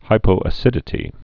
(hīpō-ə-sĭdĭ-tē)